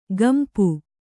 ♪ gampu